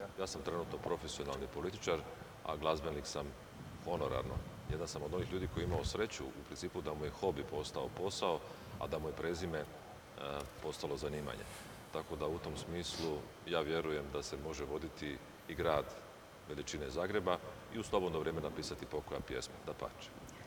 Okršaj u zagrebačkoj Esplanadi otkrio je kako izgleda kada se na jednom mjestu okupi deset političkih oponenata. Izdvojili smo zanimljive odgovore kandidata iz višesatnog sučeljavanja.